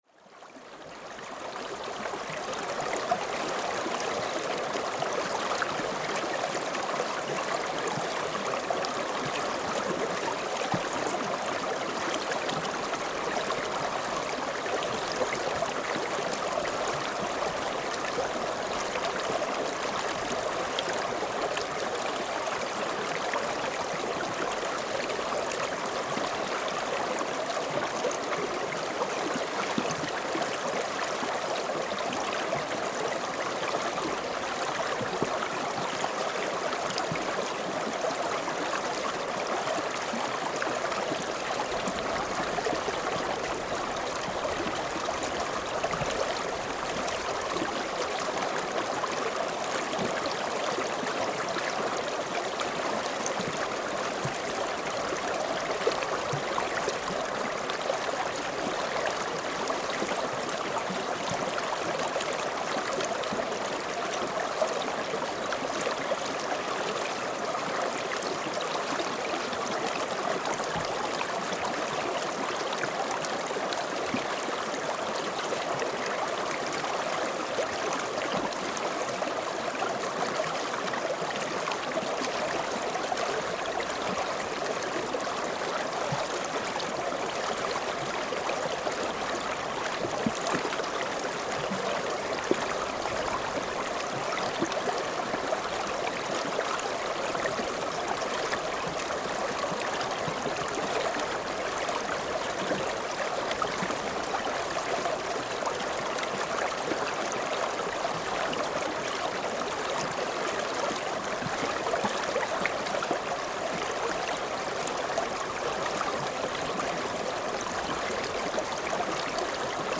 Posted in Náttúra, tagged Korg MR1000, Lækur, River, Rode Blimp, Sennheiser ME64, vinter on 5.11.2010| 1 Comment »
Lækurinn var því saklaus þar sem hann gægðist á stökum stað undan íshellunni og fyllti “ærandi” þögn með ákveðnum hljóðum á leið sinni til sjávar ______________________________ Úlfsá (wolf river) is a name of a small stream in the valley “ Dagverdardalur ” in the northwest of Iceland. This sound image of this river was recorded 4th of April in cold but nice weather between blizzard storms.
laekjanidur.mp3